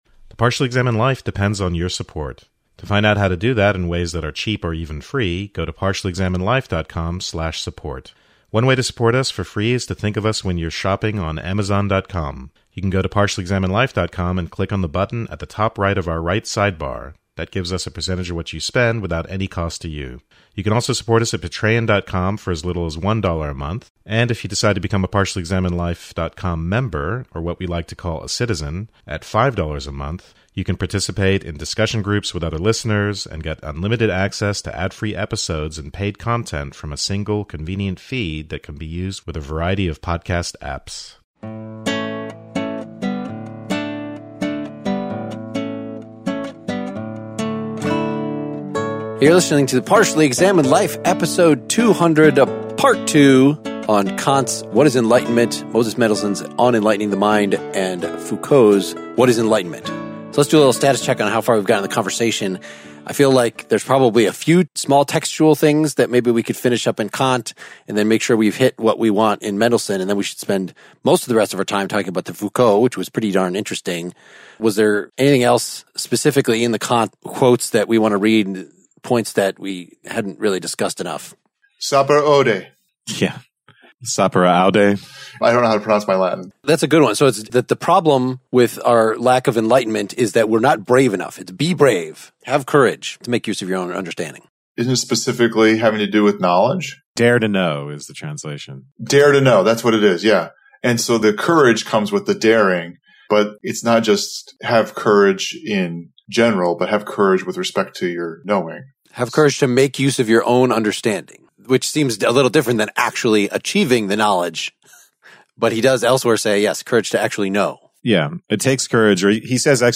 We finish up Kant (the courage to know!) and lay out the Mendelssohn (cultivation vs. enlightenment) and Foucault (ironically heroize the present!). Will this conversation enlighten you?